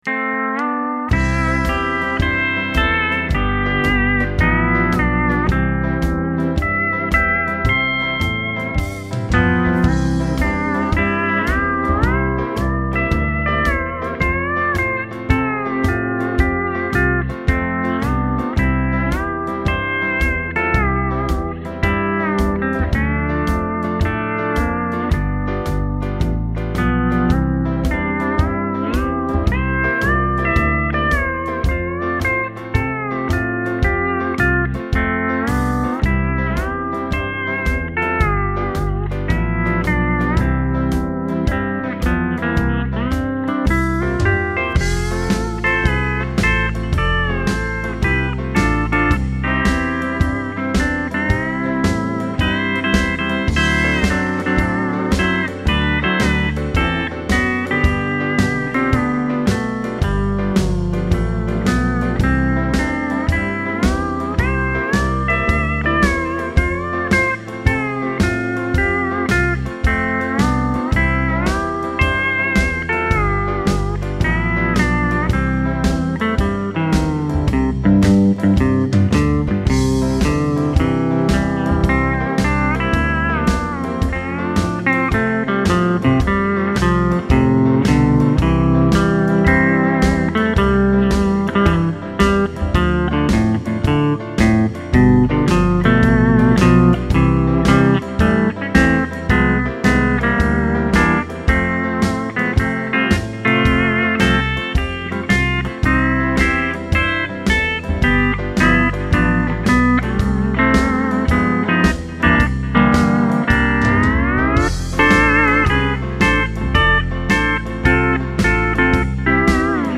Here's what the Show Pro sounds like through it (click here)